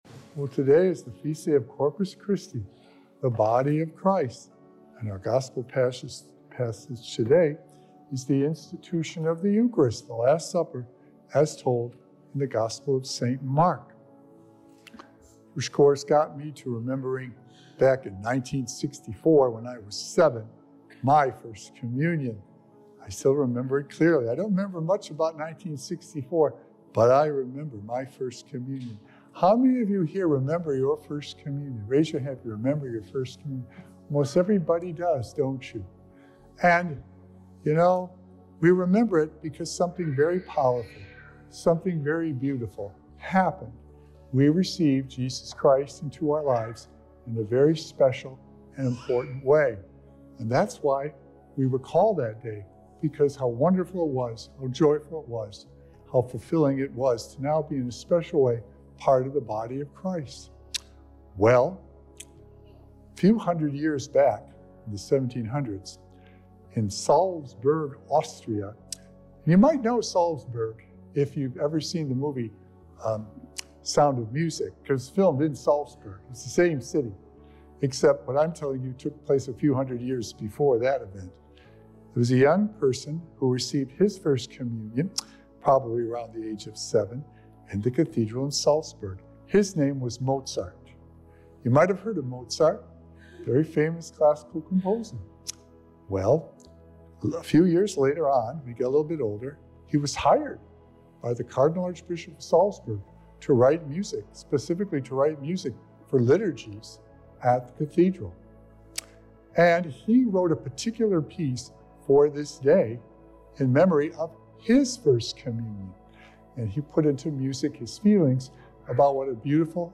Sacred Echoes - Weekly Homilies Revealed
Join us for a heartfelt homily on the Feast of Corpus Christi, reflecting on the profound significance of the Eucharist by reliving the joy of our First Communion!
Recorded Live on Sunday, June 2nd, 2024 at St. Malachy Catholic Church.